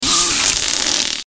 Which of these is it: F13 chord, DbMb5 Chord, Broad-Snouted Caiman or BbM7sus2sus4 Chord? Broad-Snouted Caiman